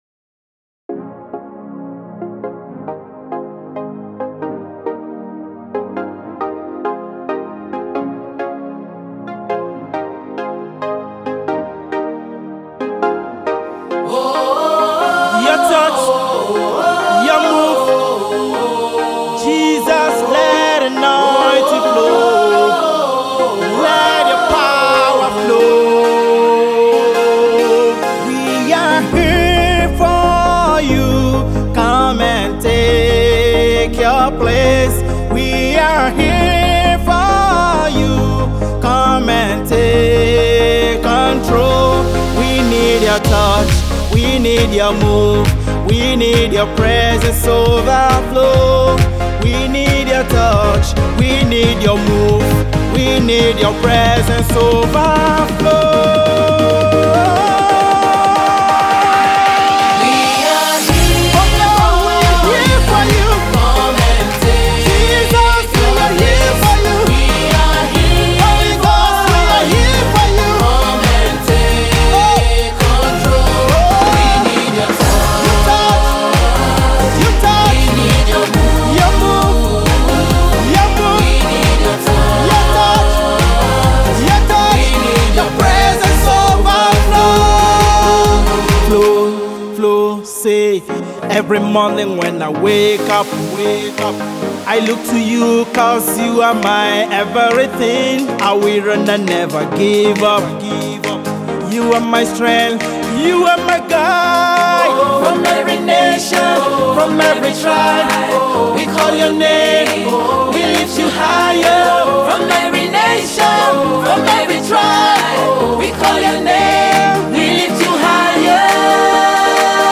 is a Lagos based Nigerian gospel